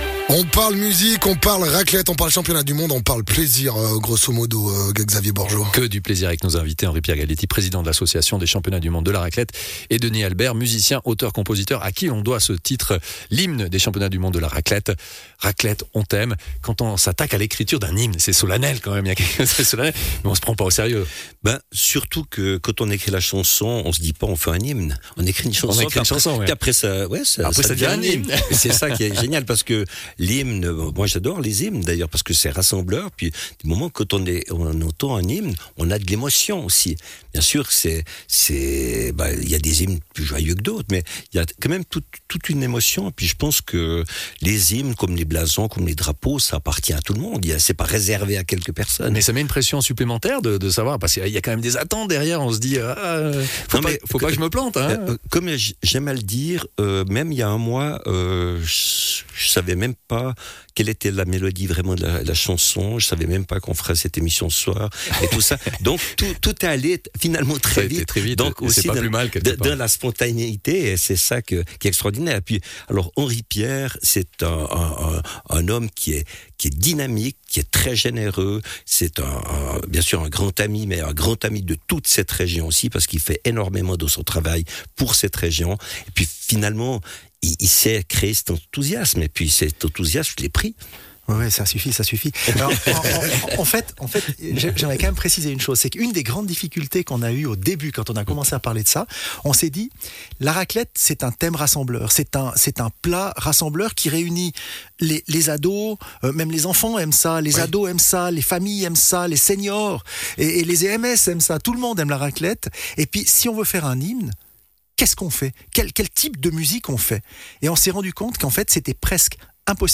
musicien, auteur, compositeur